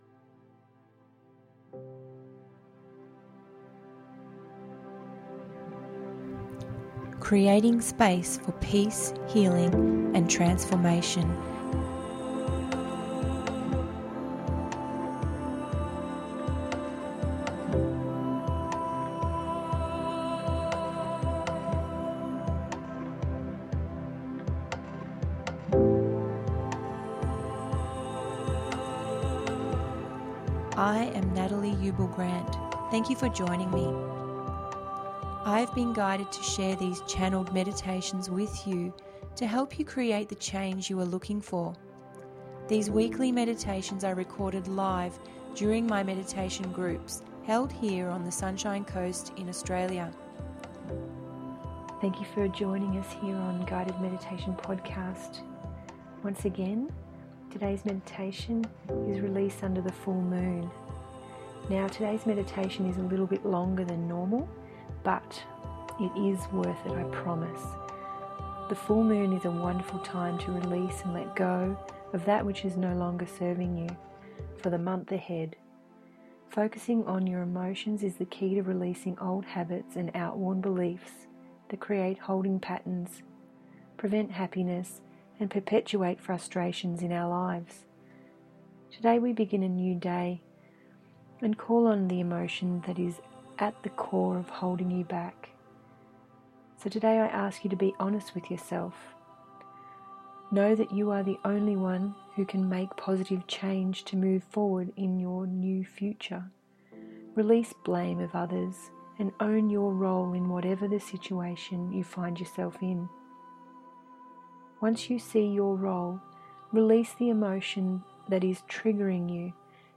Release Under The Full Moon…074 – GUIDED MEDITATION PODCAST